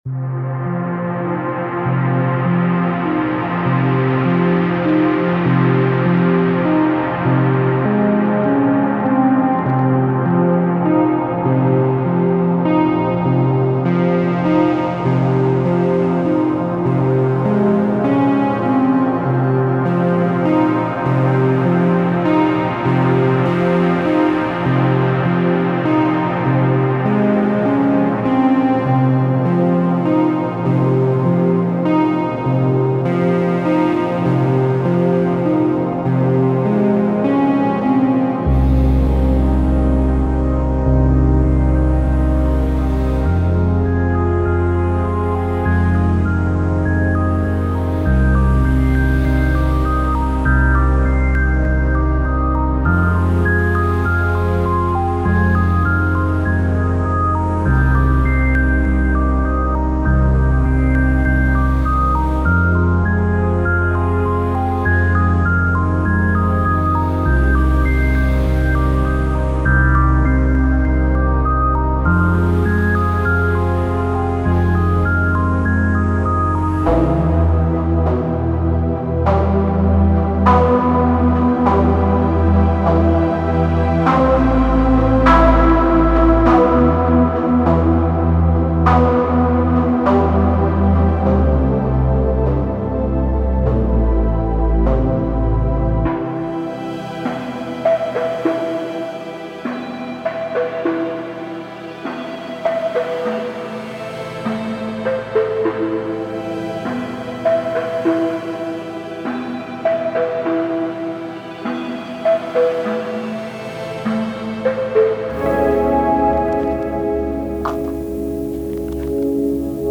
暗くドローンする大気、潜在意識に作用するテクスチャー、無限の影に溶け込む進化するメロディフラグメントまで収録。
デモサウンドはコチラ↓
Genre:Ambient
36 Atmosphere & Drone Loops
20 Pad Loops
20 Synth & Keys Loops
12 Abstract Perc Loops
8 Textures & Noise Loops